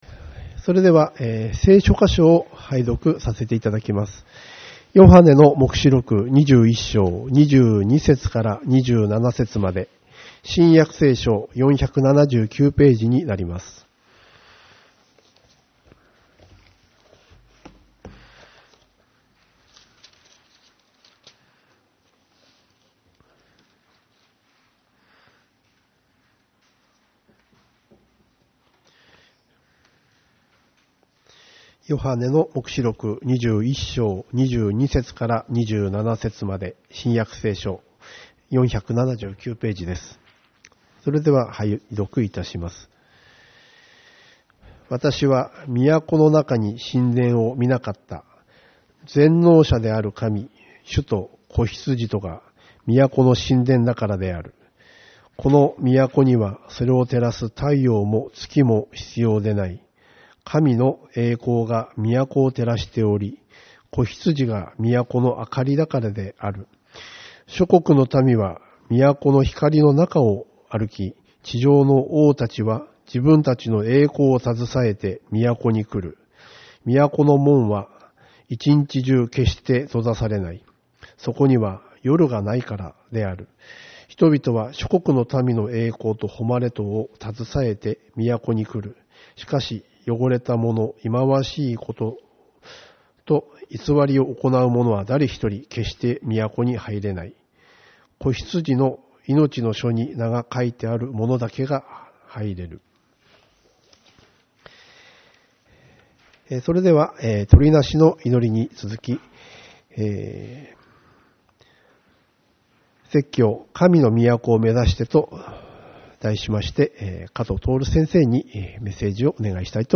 2月9日主日礼拝 「神の都を目指して」